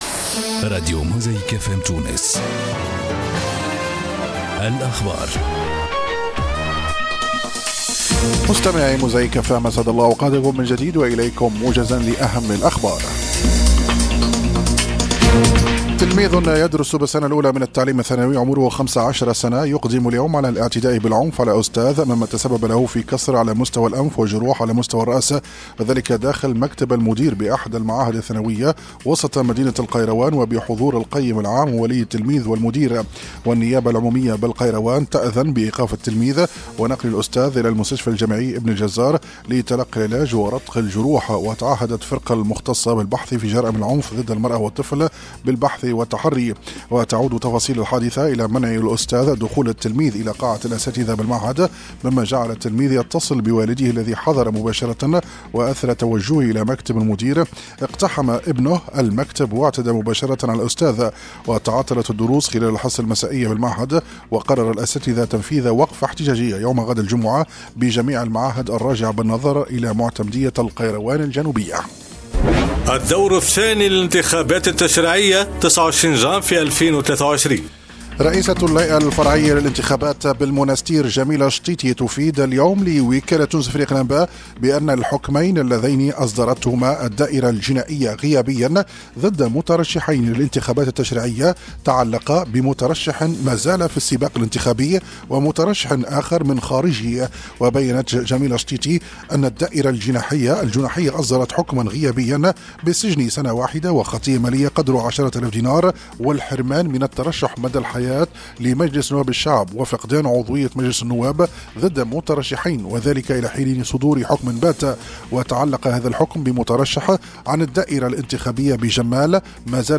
نشرات أخبار جانفي 2023